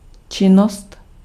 Ääntäminen
IPA: /ak.ti.vi.te/